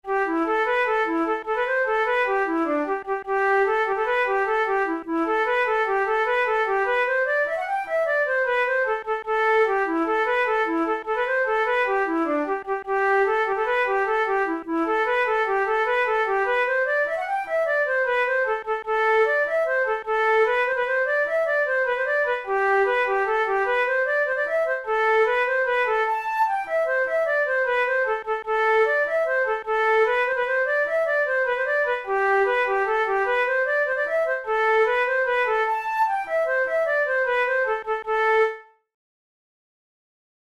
InstrumentationFlute solo
KeyA minor
Time signature6/8
Tempo100 BPM
Jigs, Traditional/Folk
Traditional Irish jig